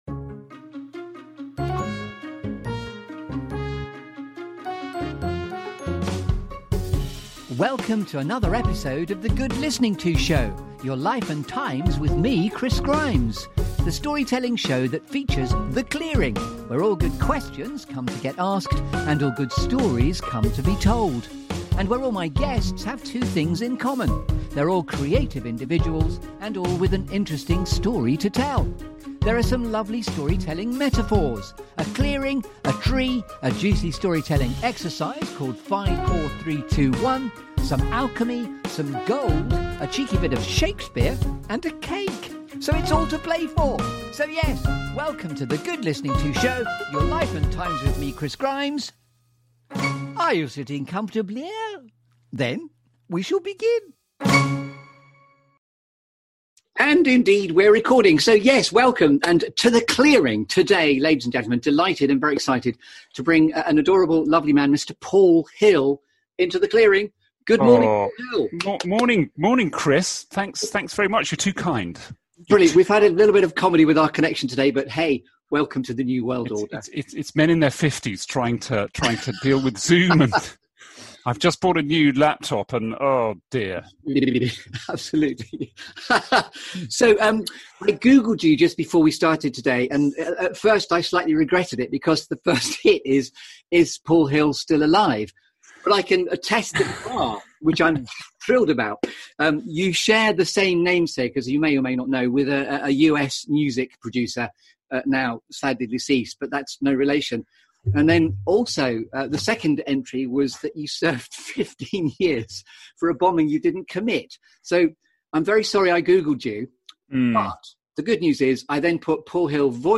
This feel-good Storytelling Show that brings you ‘The Clearing’.